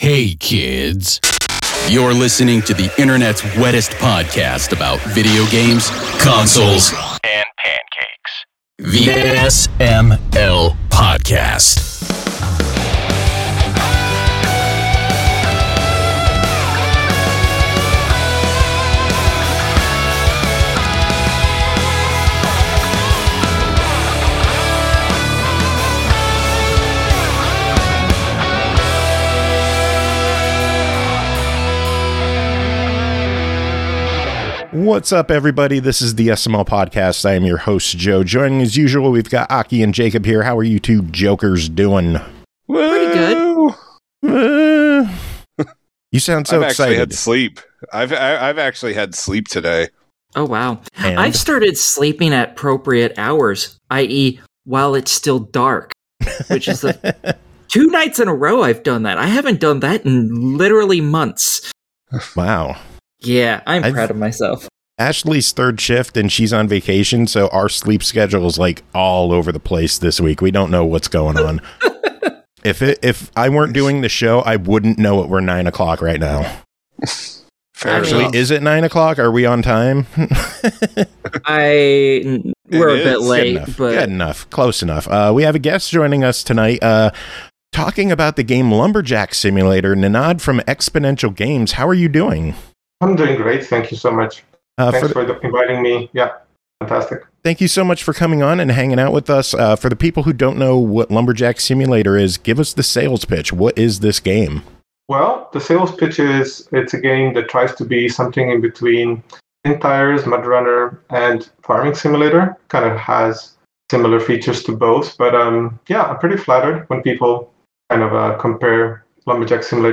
News, reviews, and a Lumberjack Simulator interview lie ahead on this interview of SML!